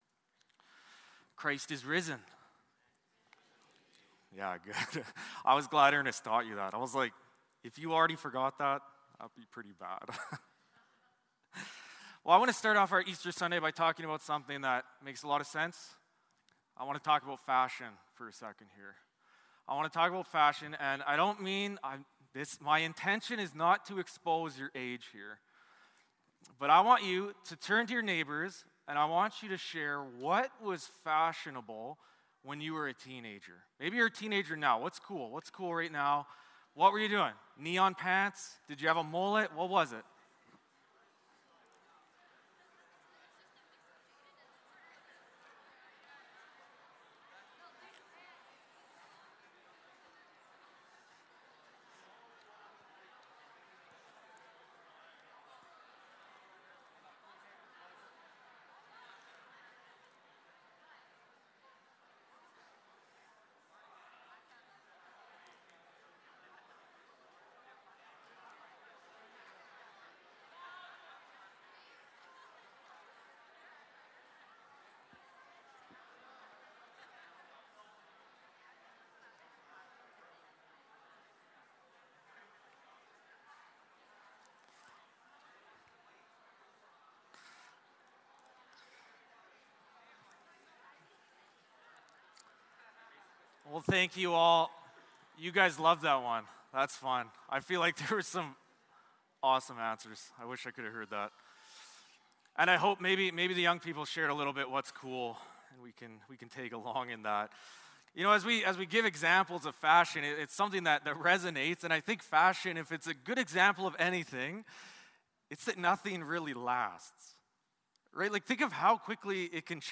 Matthew 28 Service Type: Sunday Morning Service Passage